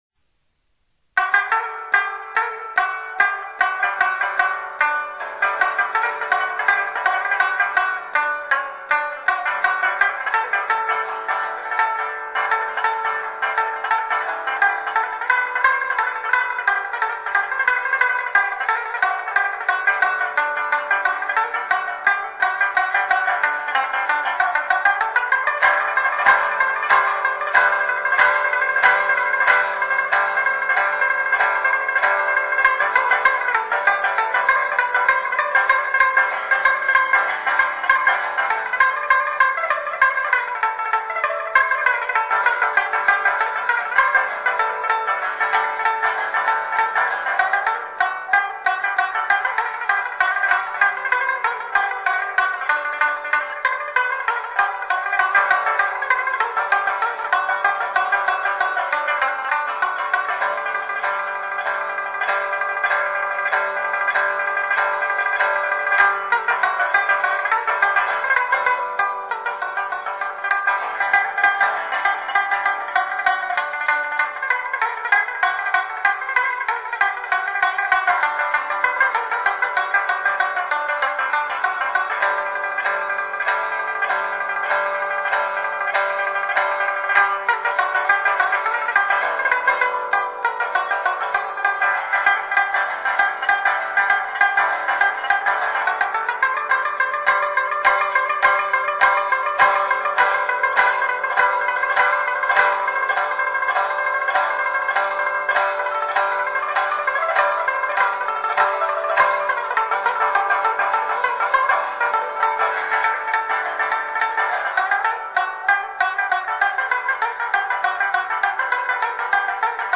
(热瓦普独奏)